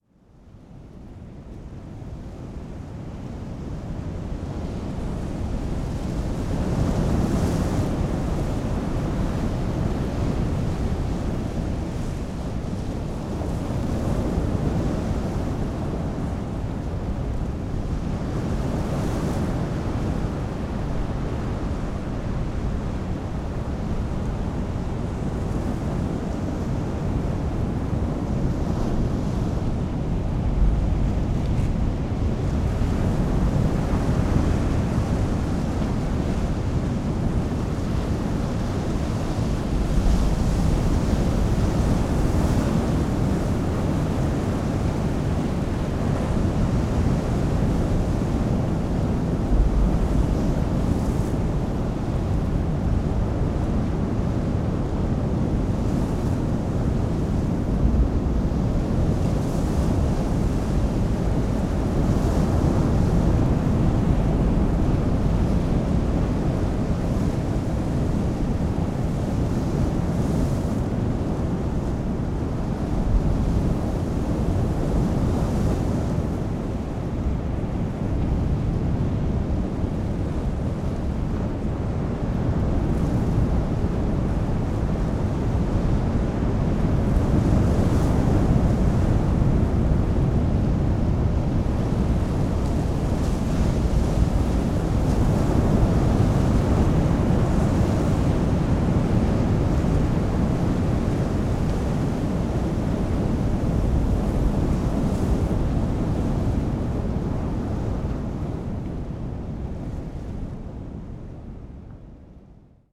ウグイス
ZOOM H6, RODE NT5 Pair（OMNI, Jecklin Disk type Stereo）2015年3月6日 千葉県
その時からウグイスのさえずりは始まっていた。